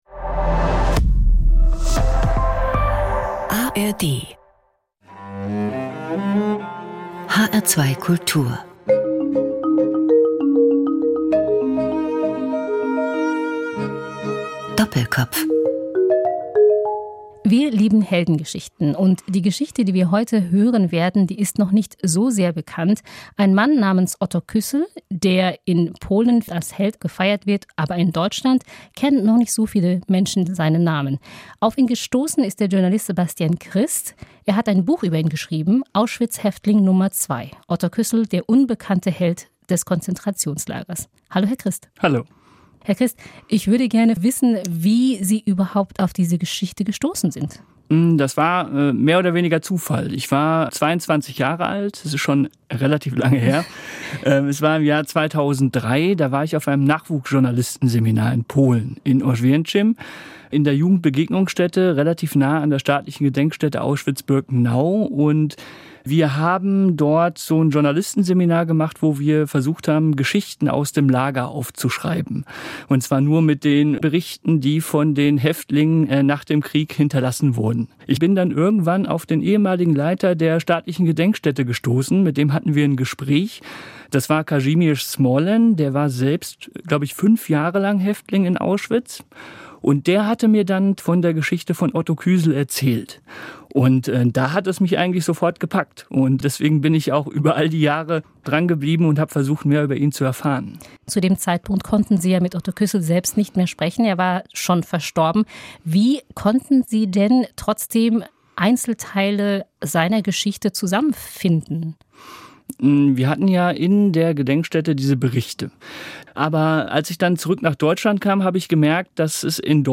Interessante Zeitgenossen - Menschen, die etwas zu sagen haben, unterhalten sich 50 Minuten lang mit einem Gastgeber über ihre Arbeit und ihr Leben.